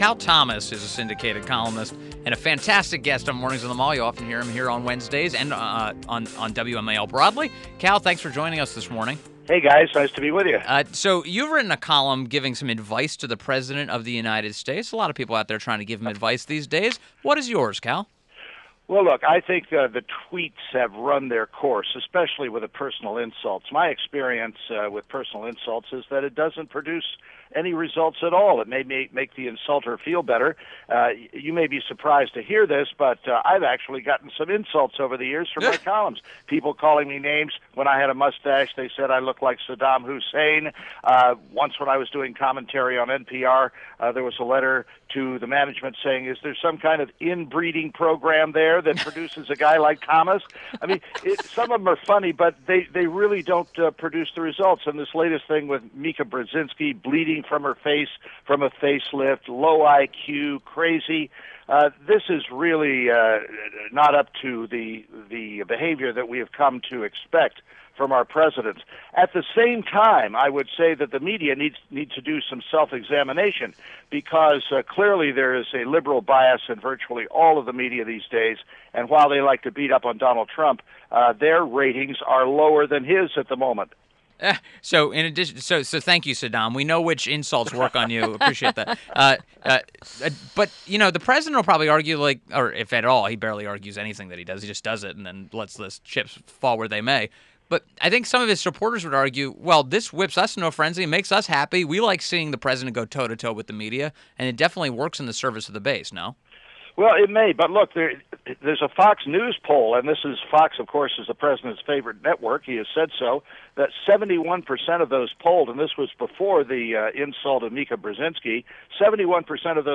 WMAL Interview - CAL THOMAS 07.05.17